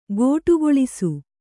♪ gōṭugoḷisu